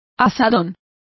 Complete with pronunciation of the translation of hoe.